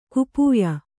♪ kupūya